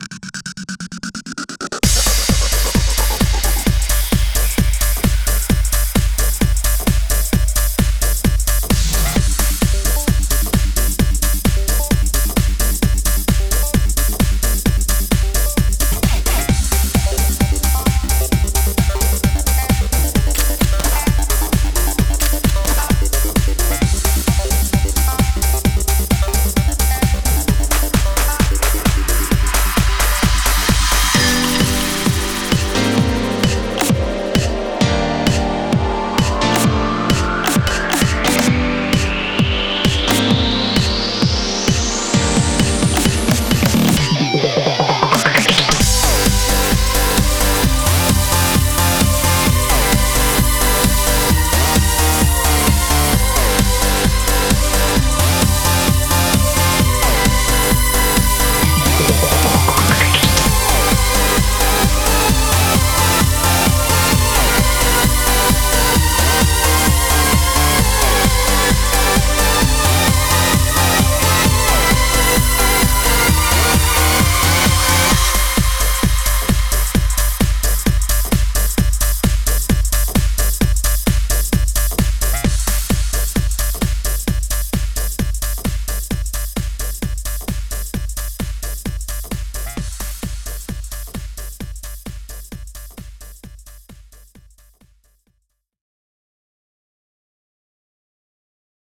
あかるい はげしい